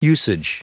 Prononciation du mot usage en anglais (fichier audio)
Prononciation du mot : usage